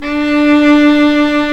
Index of /90_sSampleCDs/Roland - String Master Series/STR_Viola Solo/STR_Vla1 Bryt vb